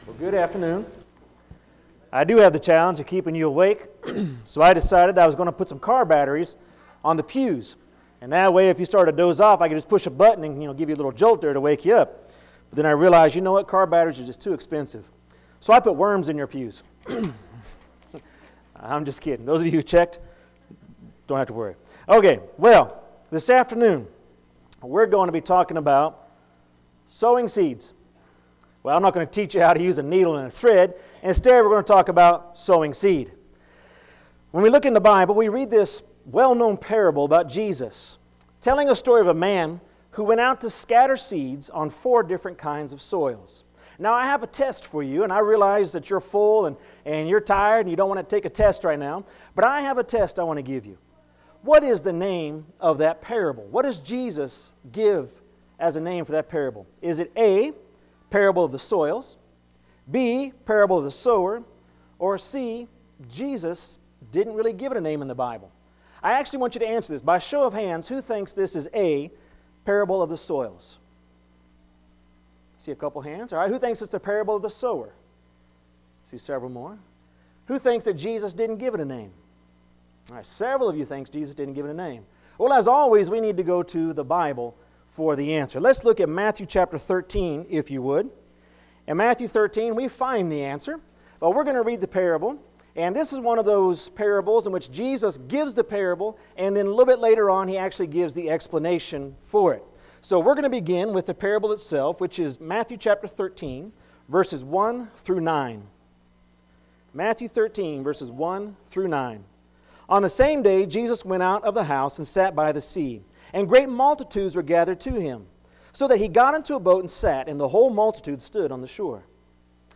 Mission Sunday PM.mp3